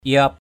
/iɔ:p/ (t.) teo, eo. shrink, thin. ké rup yut urak ni lawang aiaop lo nan?